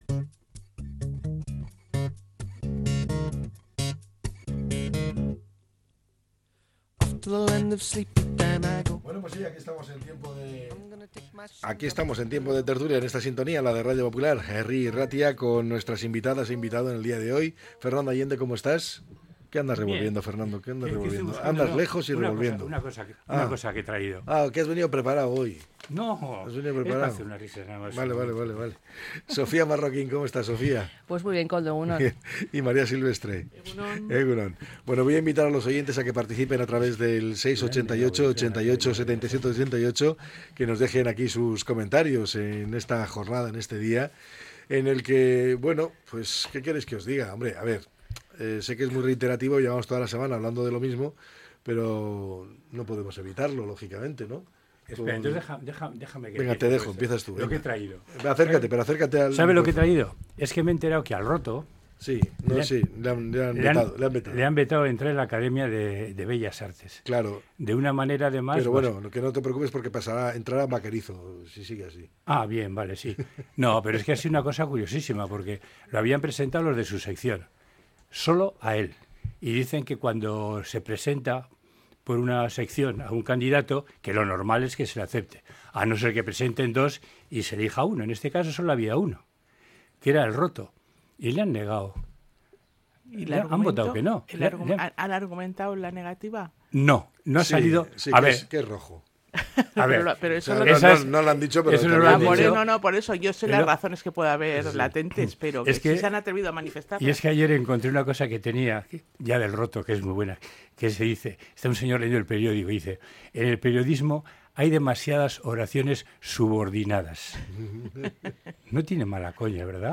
La Tertulia 11-04-25.